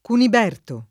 kunib$rto] pers. m. stor. — anche e meglio con -p- (forma longobarda) il re longobardo Cuniperto o Cuniberto (m. 700); solo con -b- (forma franca) san Cuniberto vescovo (m. 663) — sim. i cogn. Cuniberti, Cuniberto